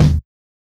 Kicks
KICK RUGGED I.wav